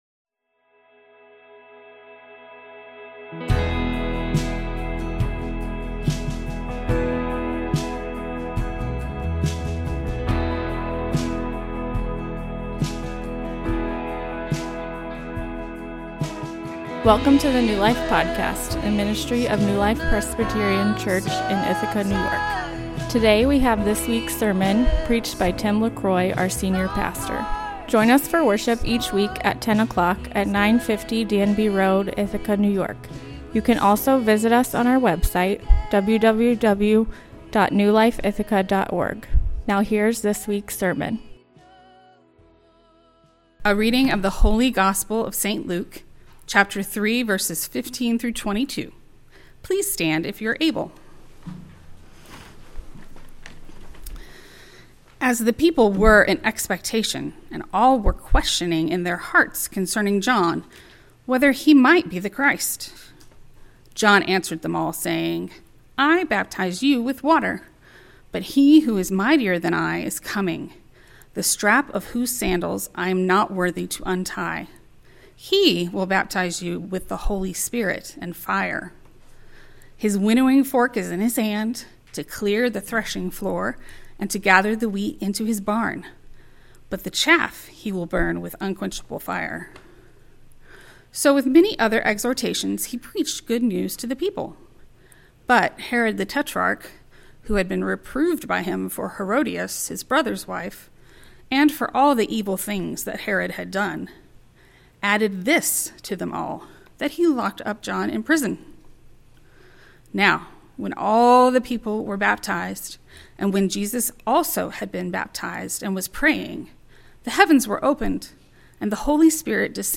Sermon Outline: 1.